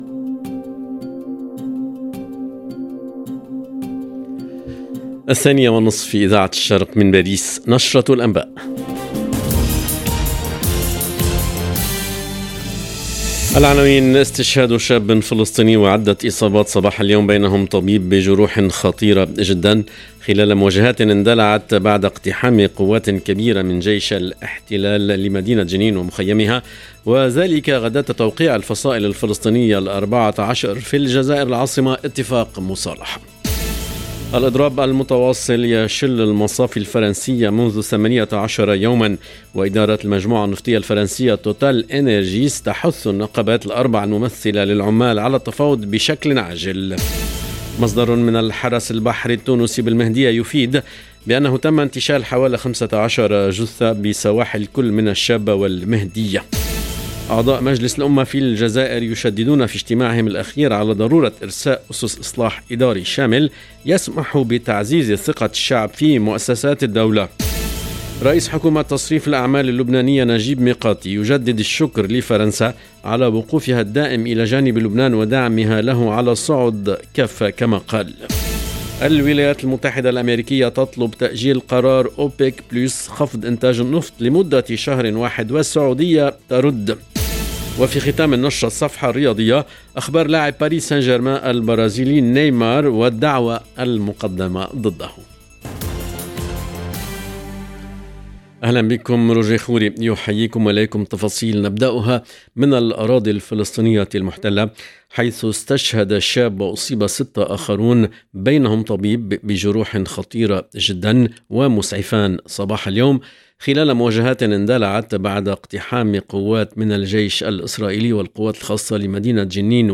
LE JOURNAL EN LANGUE ARABE DE LA MI-JOURNEE DU 14/10/22